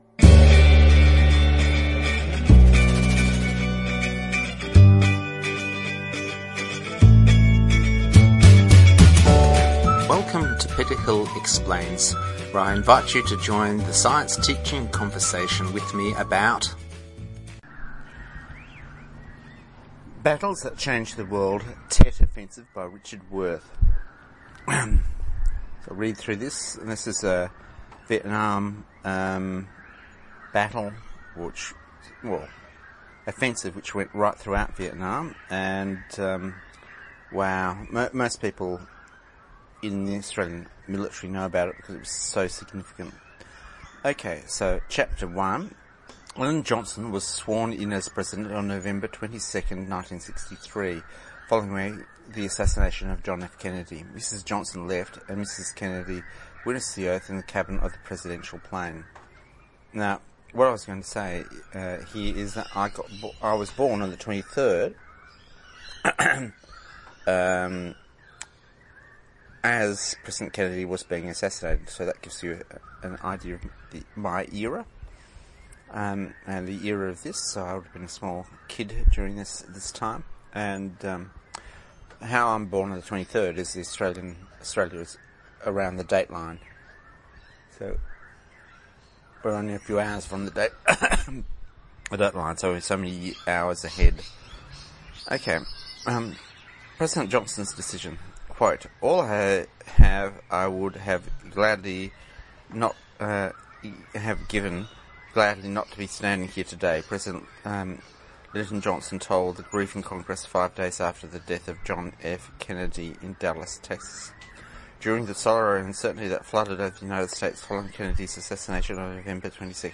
Reading a short explanation of the Battle that the North Vietnamese lost but won the war MP4 recording MP3 recording ...